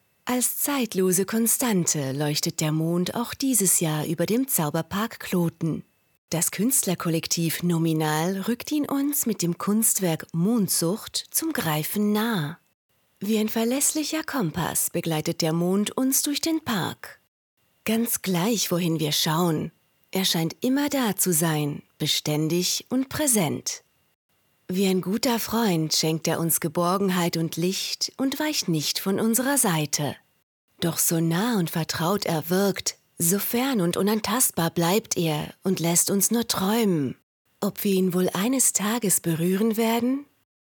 Audiodeskription